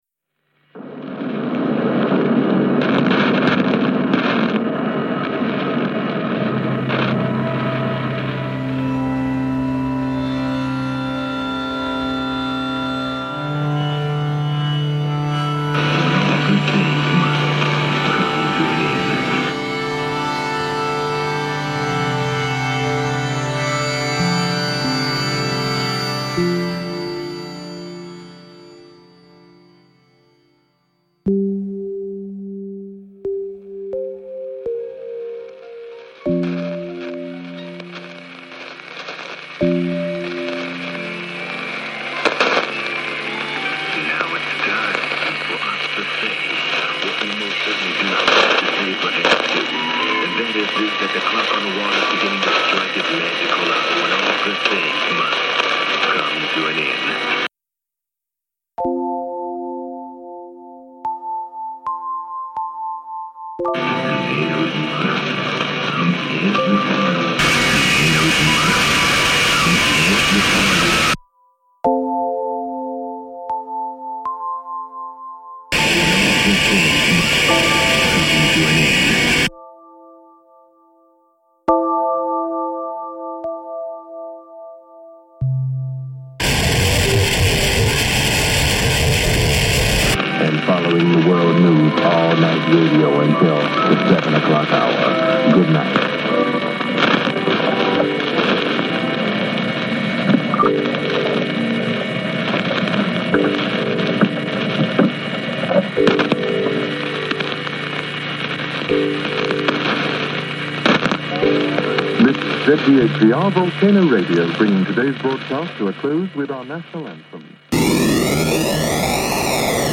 After all the static, the sudden silence was blissful.
I also decided to flow a brief, heavily processed field recording of molten lava under “God Save The Queen,” because, why not?